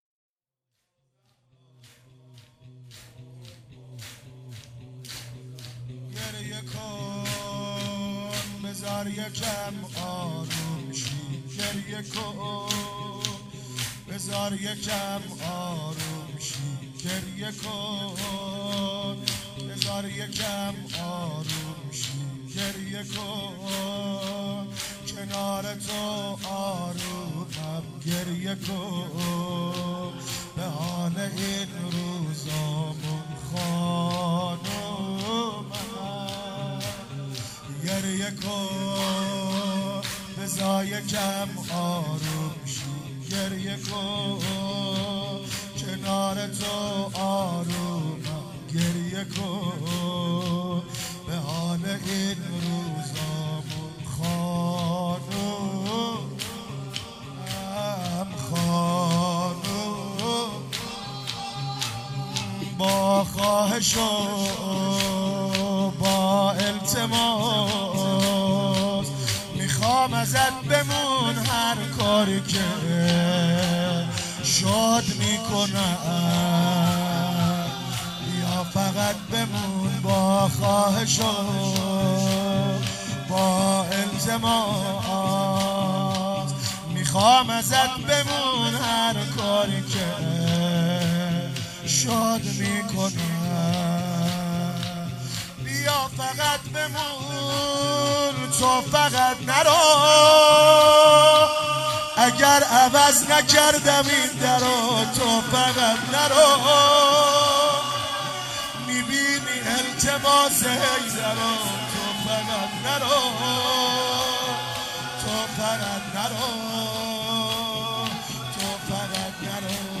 فاطمیه 95
مداحی